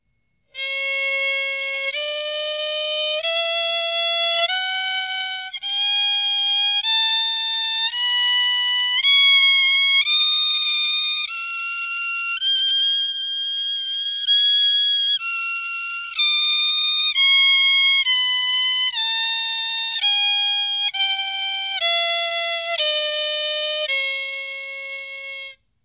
Đàn cò líu
[IMG]àn Cò Líu trong trẻo, chói sáng, biểu đạt những tình cảm đẹp đẽ, cao thượng, vui tươi, sôi nổi... có thể gay gắt, sắc nhọn nhất là ở những âm cao.